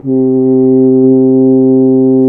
BRS F HRN 06.wav